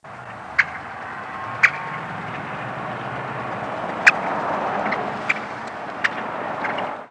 Great-tailed Grackle diurnal flight call
"Khek" calls from bird in flight.